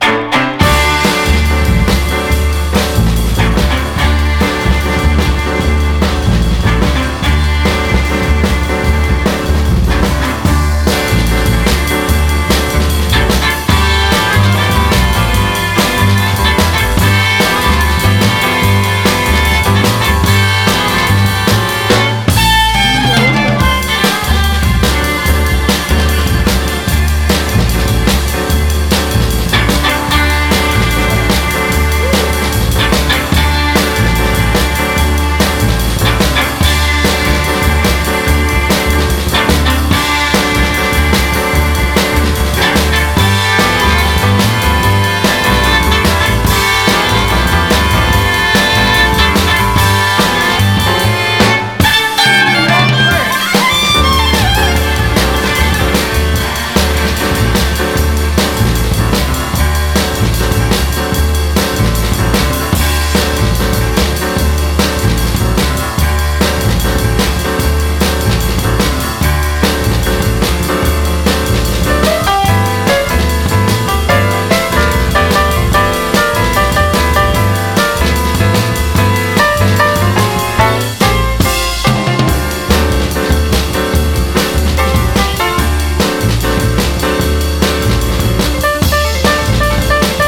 SOUL / SOUL / 70'S～ / DISCO
レア・ディスコ45！
チューバが奏でる胸キュン・メロディーが最高！
フィリー・ディスコ風に始まるのですが、主旋律を奏でるのはなんとチューバ！